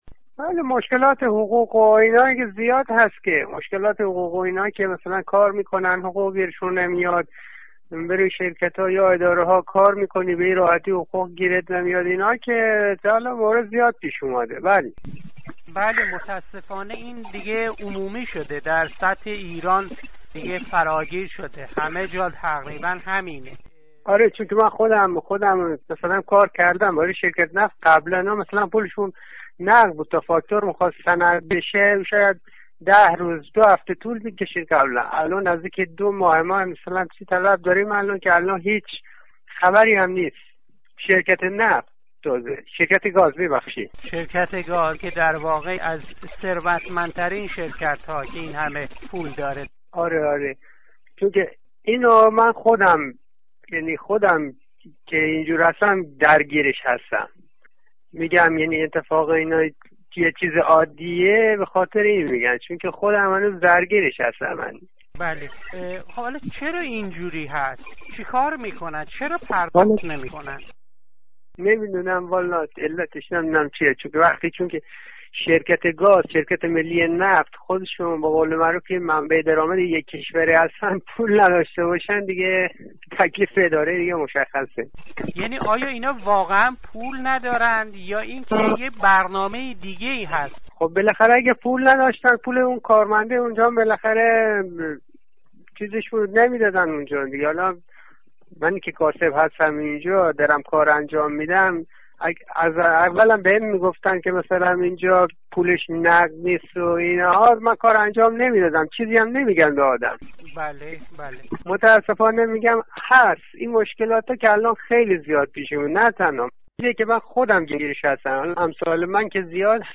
يكي از شهروندان در بندرعباس در گفتگو با راديو ندا از حقوق هاي معوقه؛ آموزش و پرورش پولي و دغدغه هاي والدين دانش آموزان مي گويد.